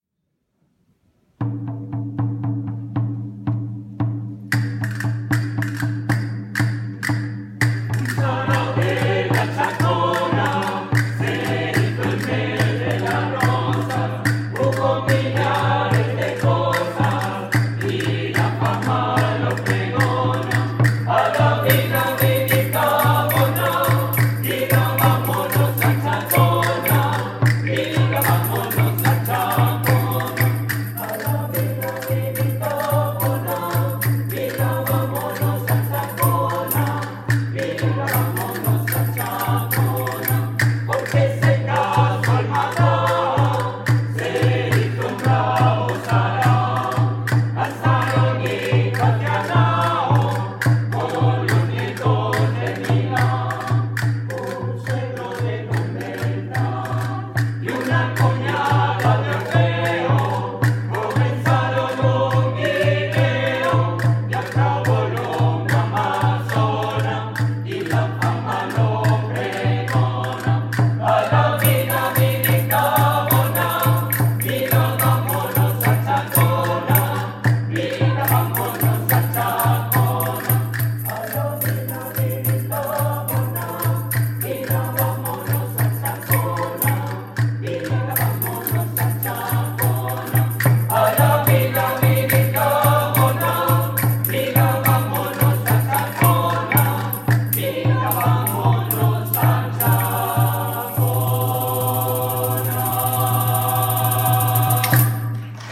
La Chacona (grabación en directo)